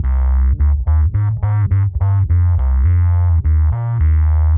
Index of /musicradar/dub-designer-samples/105bpm/Bass
DD_PBassFX_105C.wav